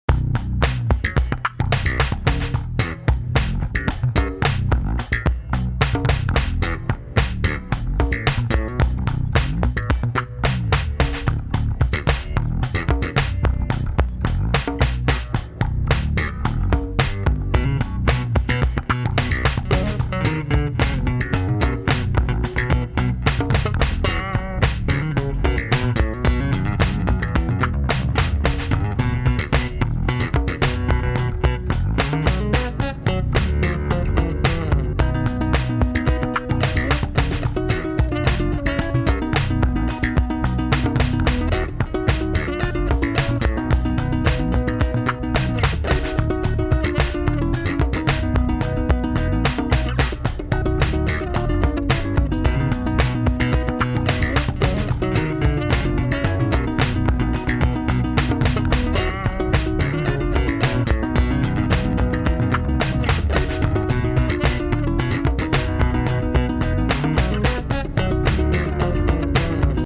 home of the daily improvised booty and machines -
bass grooves